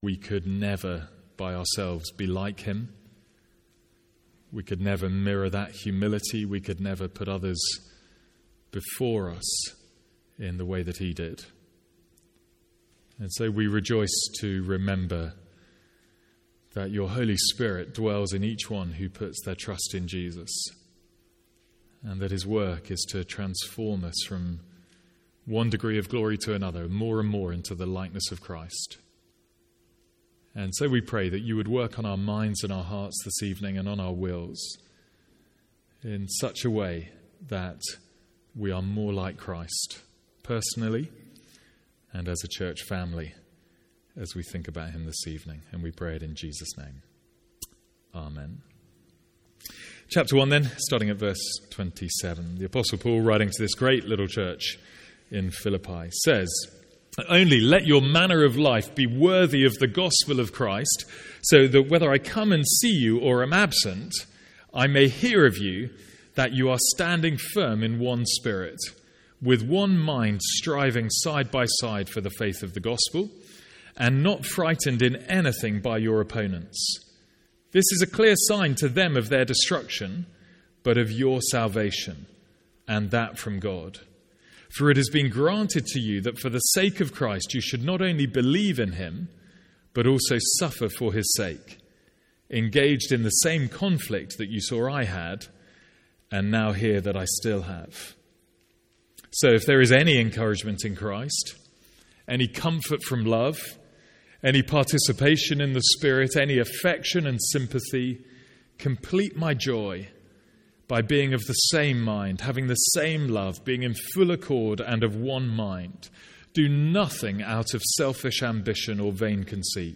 From our Christmas evening sermons.